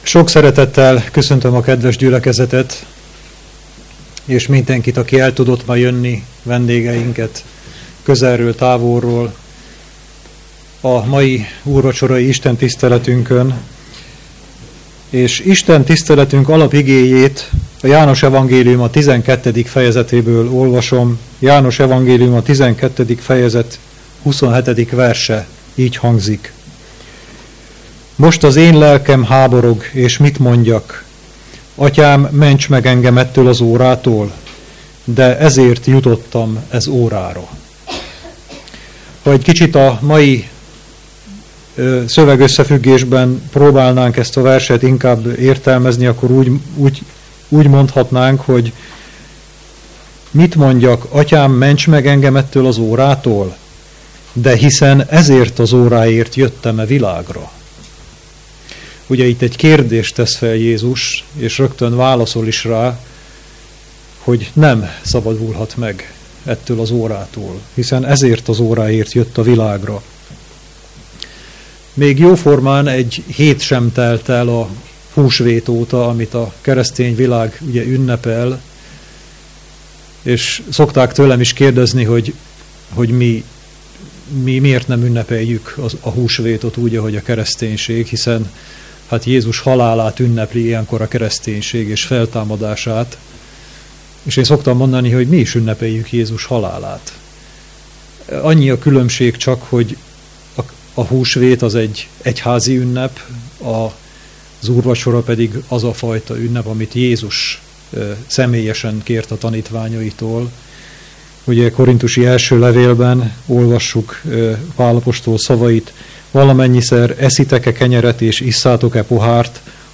Szombati igehirdetés